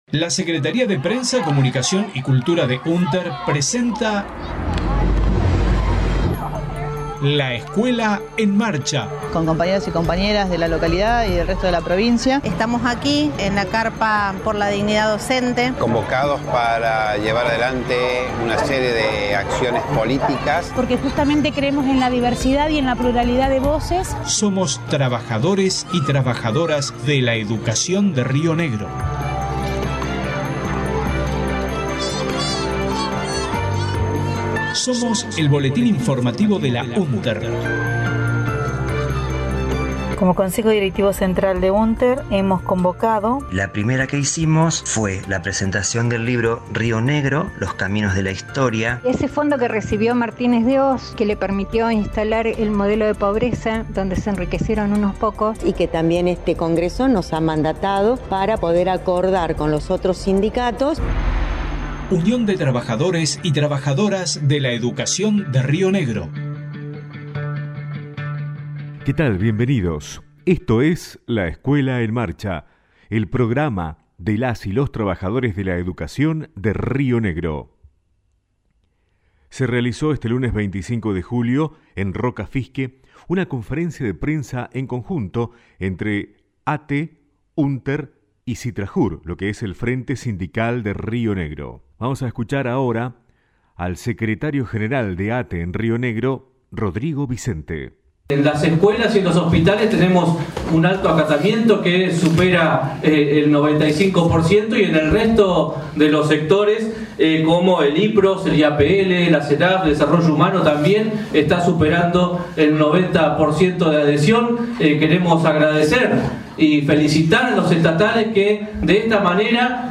media LEEM, 26/07/22: Audio conferencia de prensa del Frente Sindical de Río Negro, 26/07/22 .